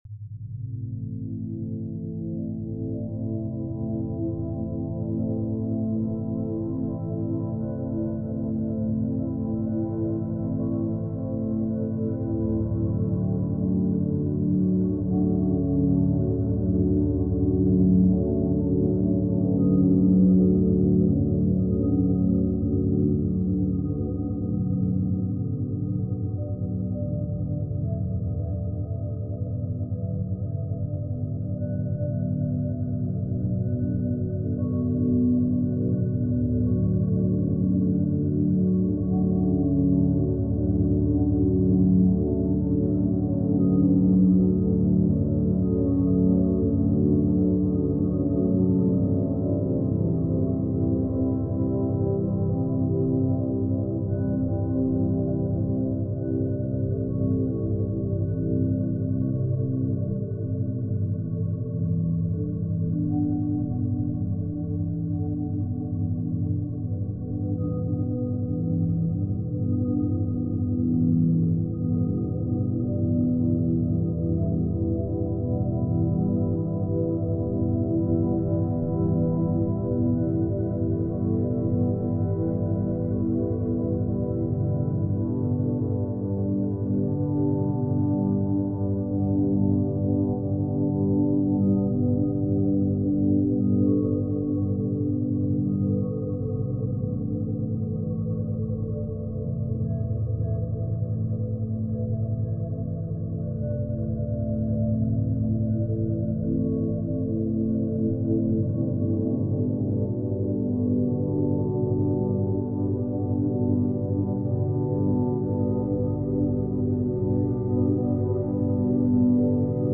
9hz - Alpha Binaural Beats - Flow State
Calm Chic sound rituals - a softer way to focus.